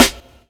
FG_SNR.wav